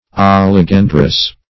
Search Result for " oligandrous" : The Collaborative International Dictionary of English v.0.48: Oligandrous \Ol`i*gan"drous\, a. [Oligo- + Gr.